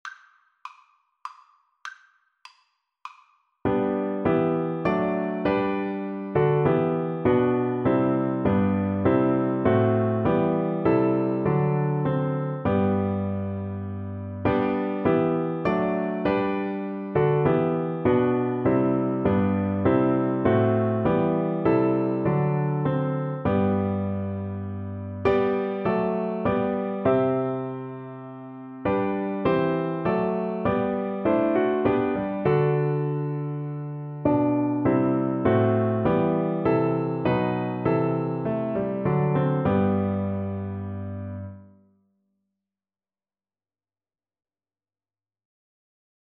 3/4 (View more 3/4 Music)
Classical (View more Classical Viola Music)